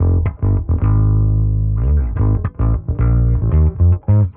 Index of /musicradar/dusty-funk-samples/Bass/110bpm
DF_PegBass_110-F.wav